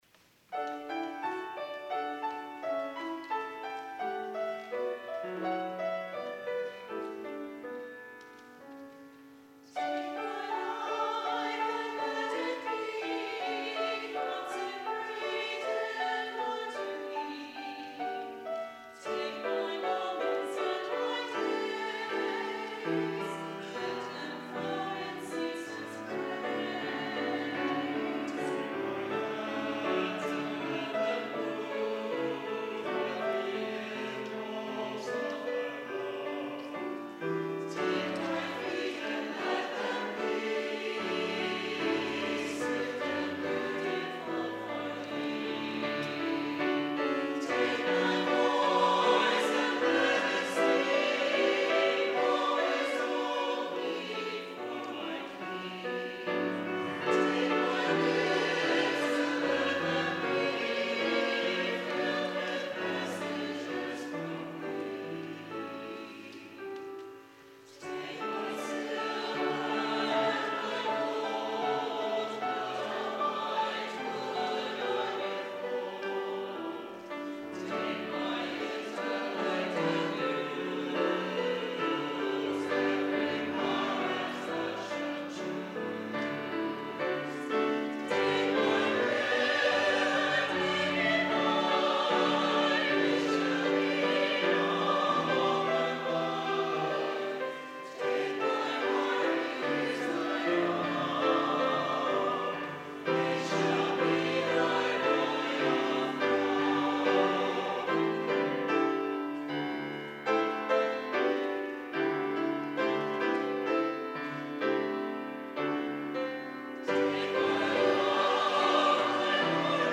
Chancel Choir
piano